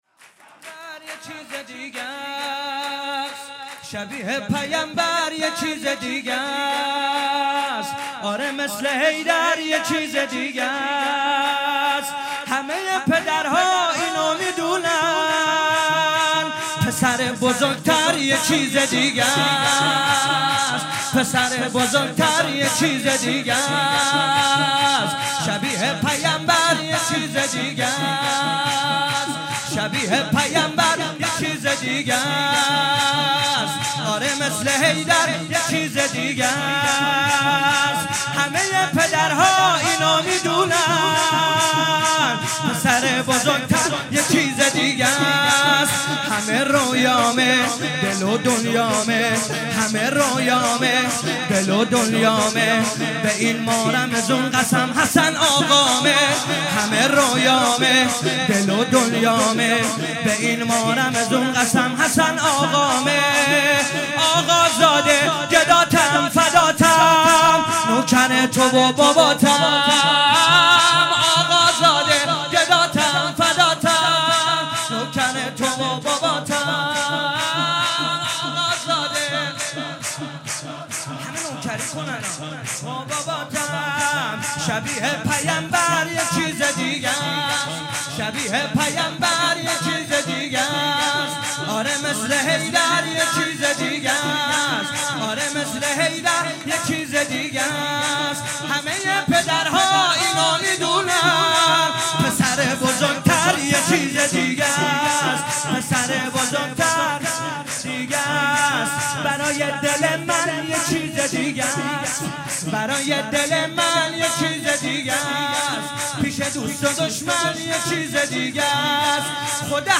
مراسم شب شانزدهم ماه مبارک رمضان با مداحی حاج محمود کریمی در امام زاده علی اکبر چیذر برگزار گردید